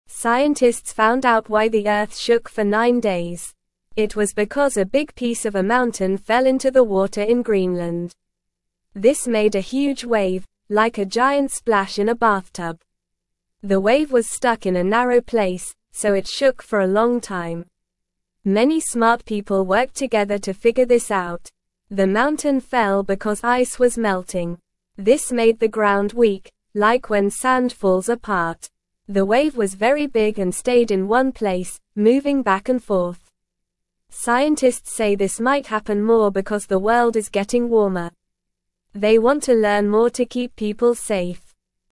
Normal
English-Newsroom-Beginner-NORMAL-Reading-Big-Wave-Shook-Earth-for-Nine-Days-Straight.mp3